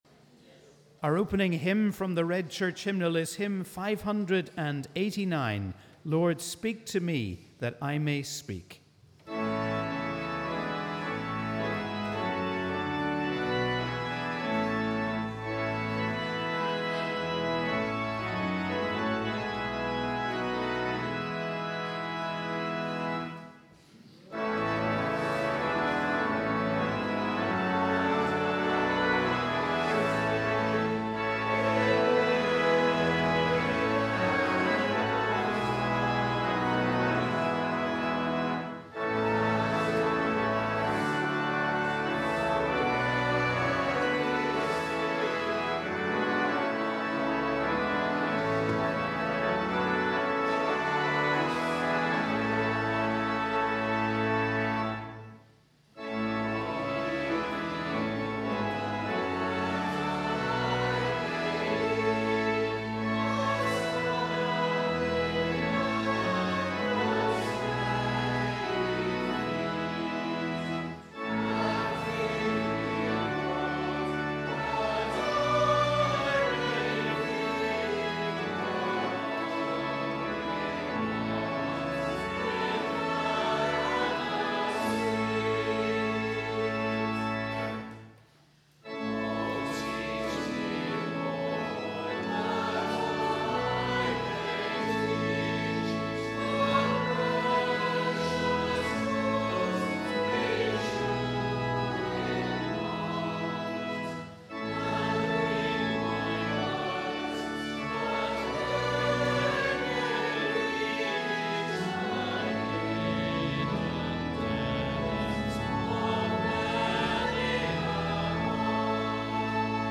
We warmly welcome you to our service of Morning Prayer on the 16th Sunday after Trinity.